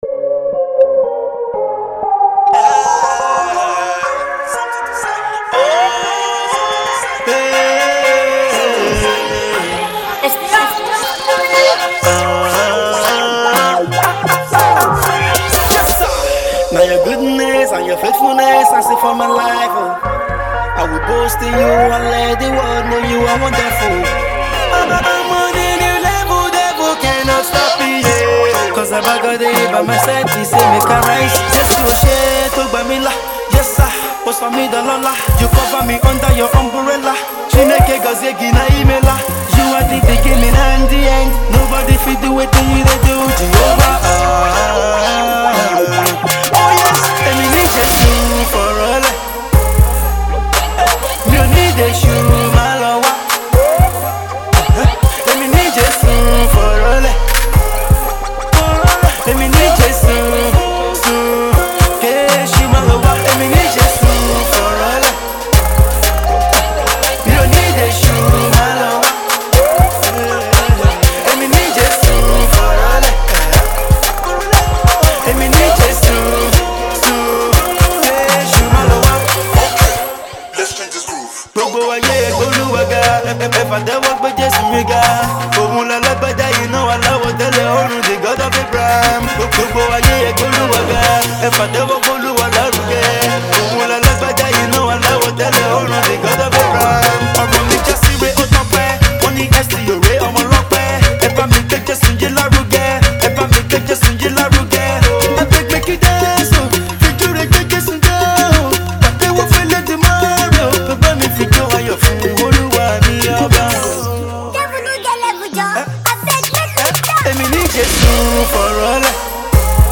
The song is a fusion of trap and afro sound.